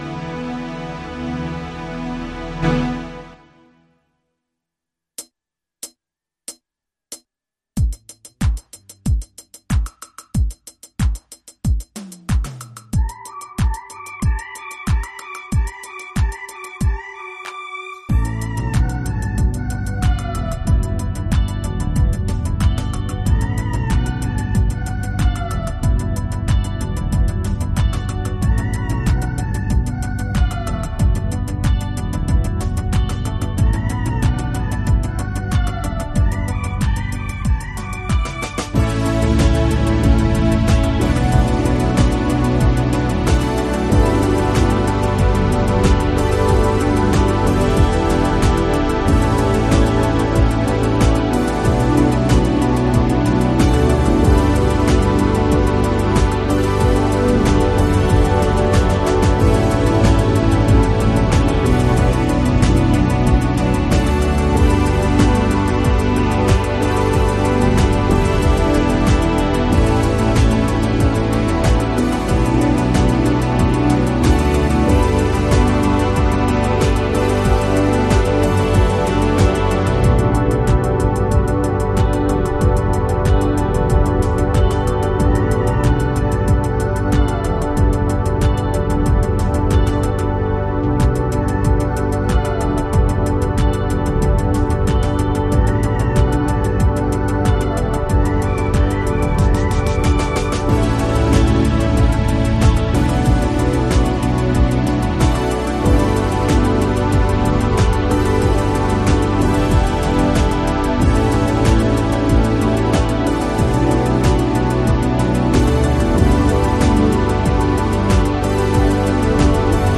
MP3 Instrumental INSTRUMENTAL VERSION